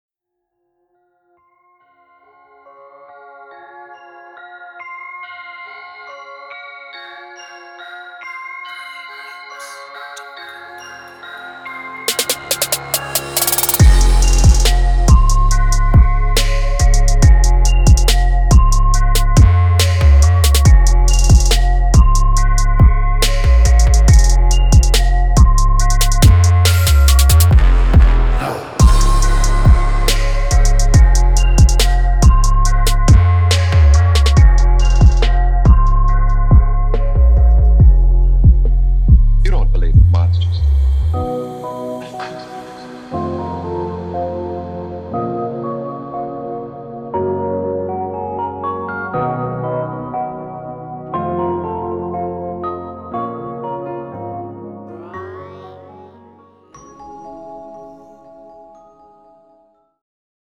Drill